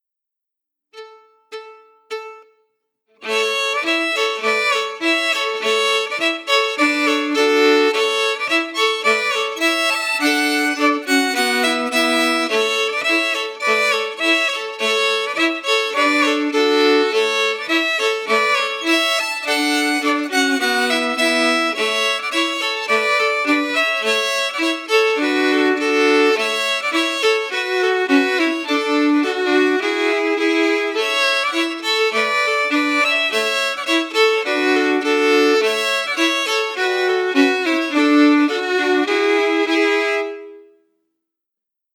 Key: A
Form: Polka
Harmony emphasis